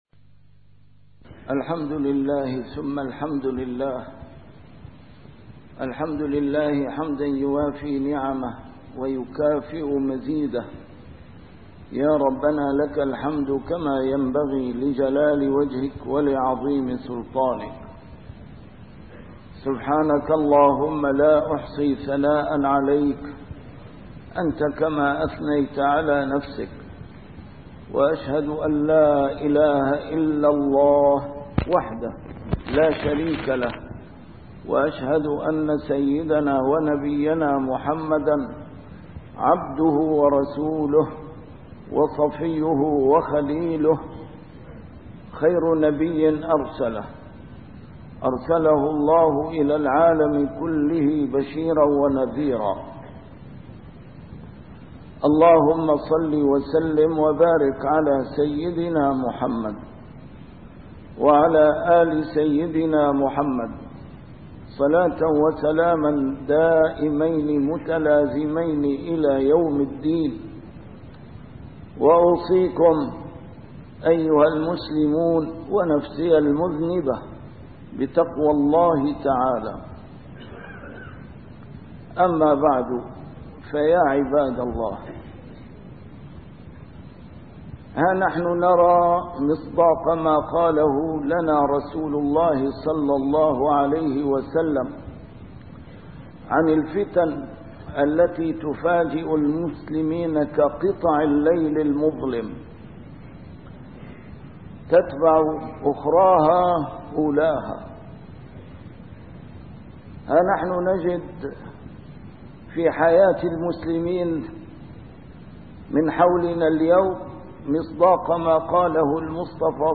A MARTYR SCHOLAR: IMAM MUHAMMAD SAEED RAMADAN AL-BOUTI - الخطب - مجالس الذكر .. العاصم من الفتن